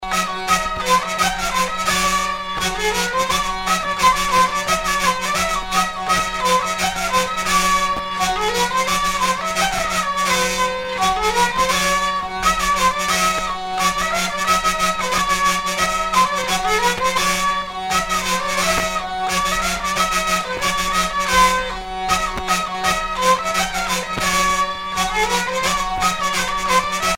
danse : scottich trois pas